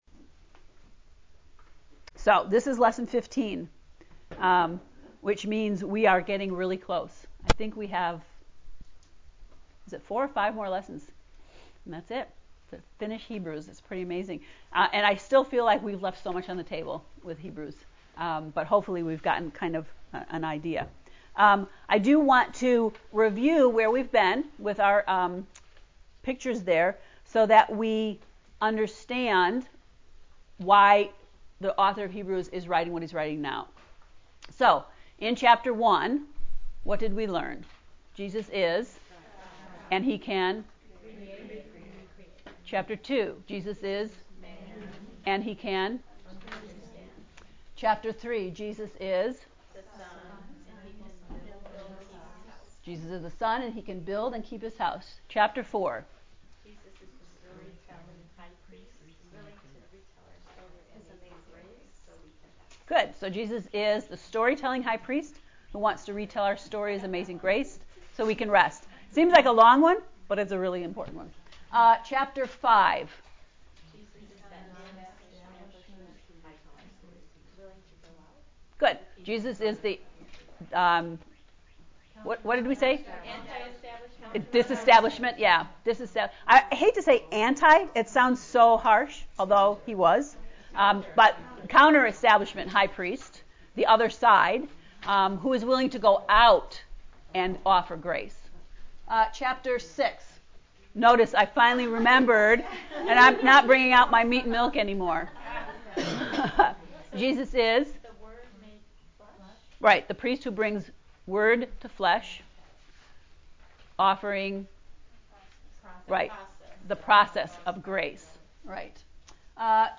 heb-ii-lecture-15.mp3